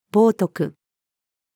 冒涜-female.mp3